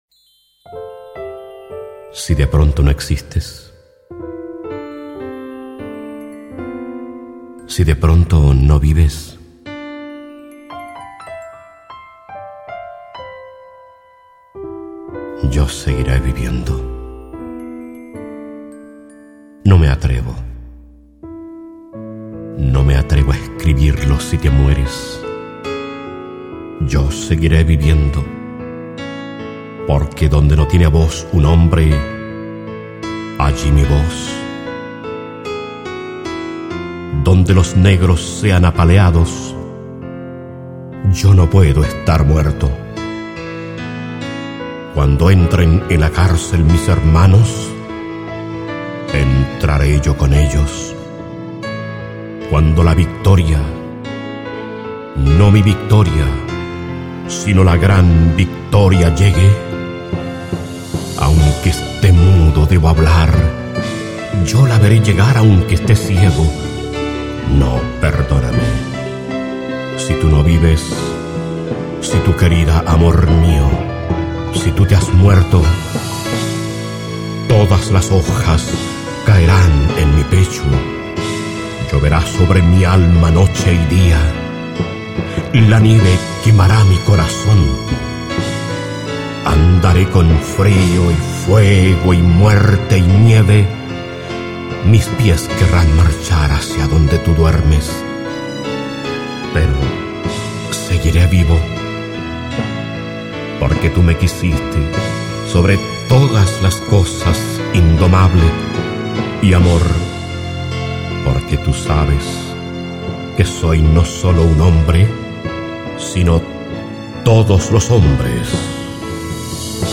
La Muerta, poema de Neruda